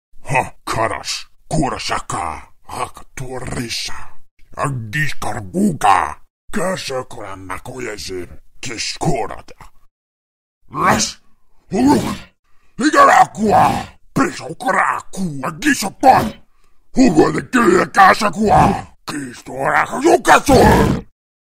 Звуки болтовни
Оркские разговоры